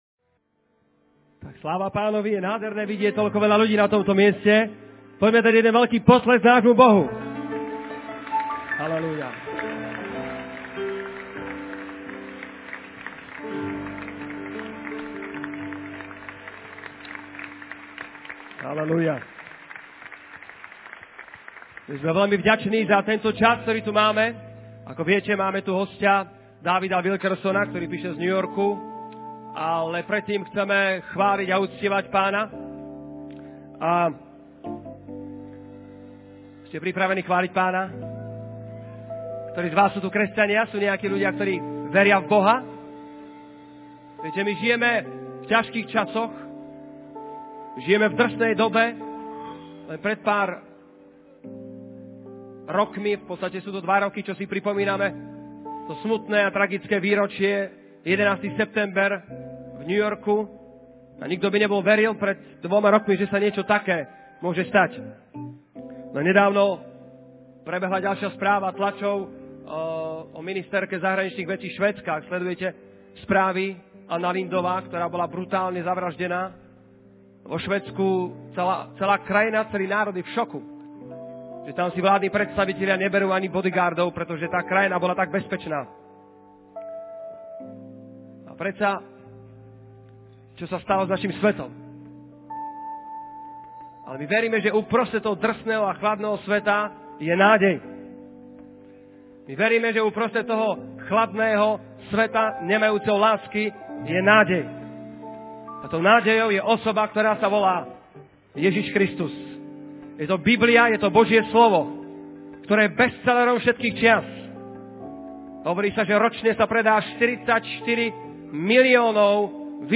The Cry Without a Voice - Slovakia Conference 2003 (Slovakian/english) by David Wilkerson | SermonIndex